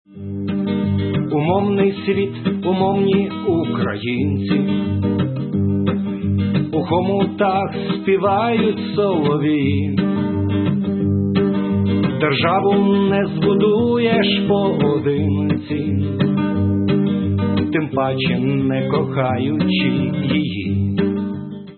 Бардівські пісні